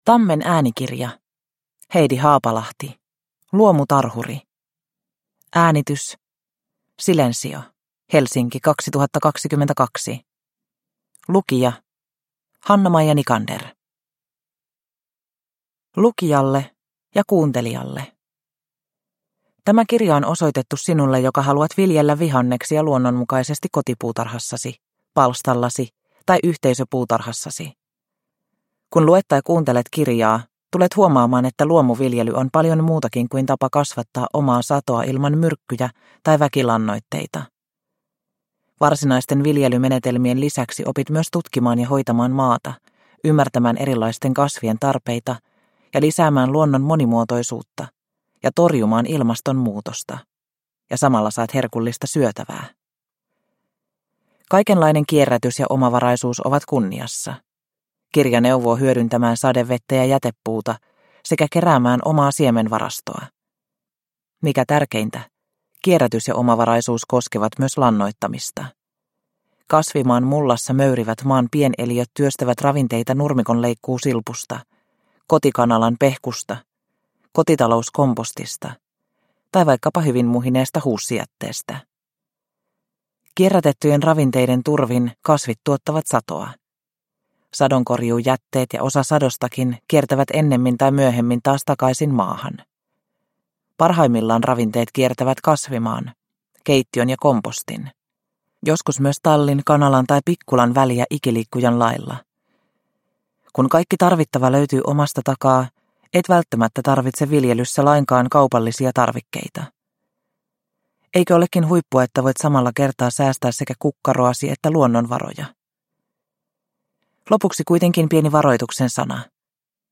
Luomutarhuri – Ljudbok – Laddas ner